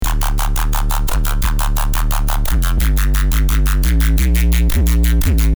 ❇ Ready for BASS HOUSE, TRAP, UKG, DRUM & BASS, DUBSTEP and MORE!
FR - Frog 2 [Dmin] 174BPM
FR-Frog-2-Dmin-174BPM.mp3